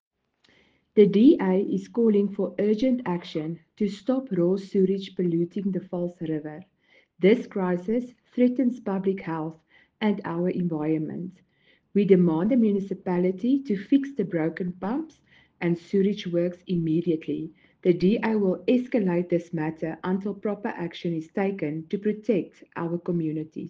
Afrikaans soundbites by Cllr Marelize Boeije and